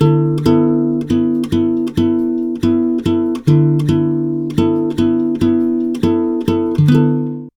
140GTR D7  5.wav